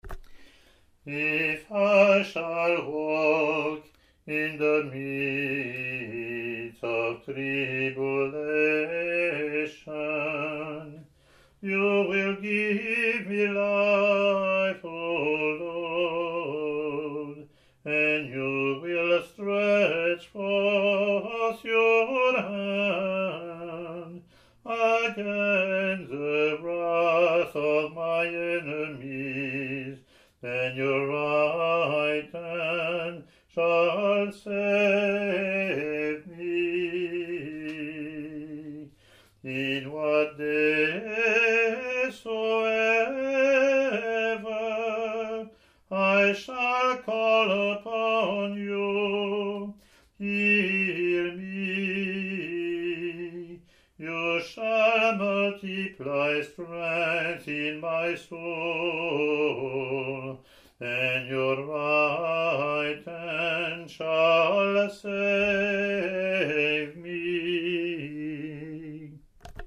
English antiphon – English verseLatin antiphon)